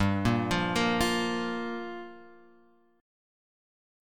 Gm7 chord {3 1 3 3 x 1} chord